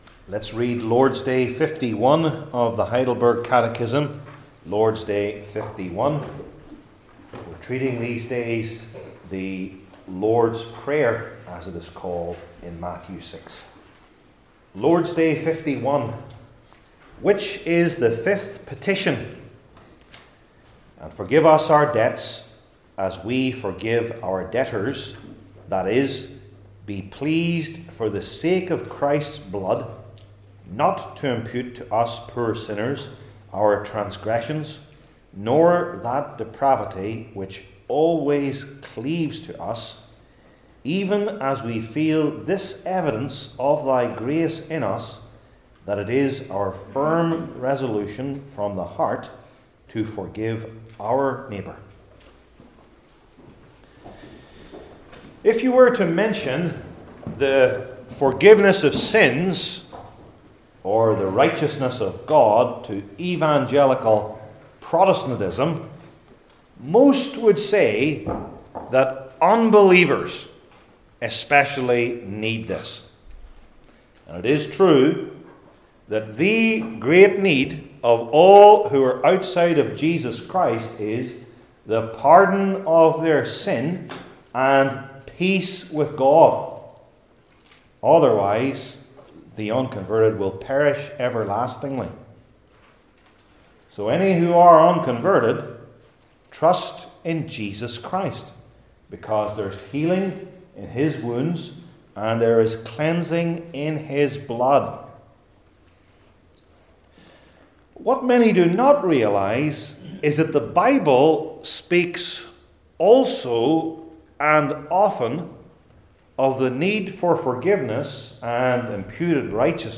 Heidelberg Catechism Sermons I. The Necessity of It II.